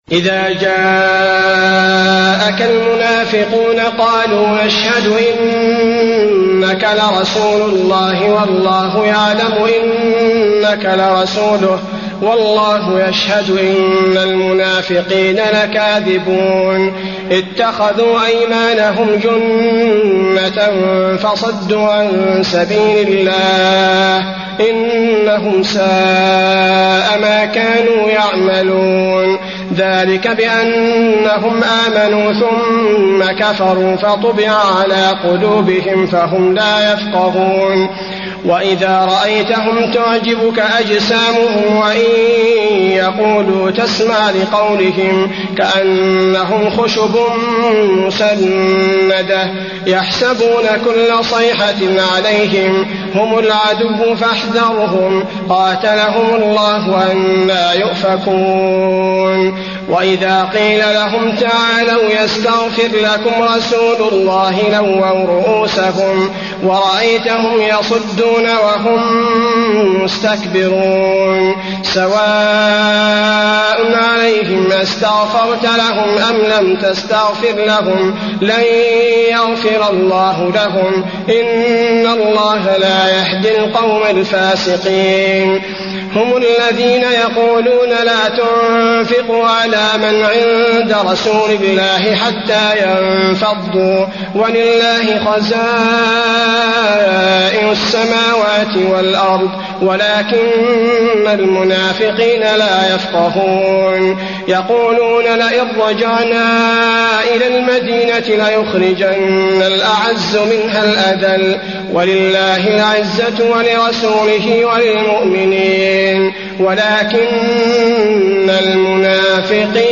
المكان: المسجد النبوي المنافقون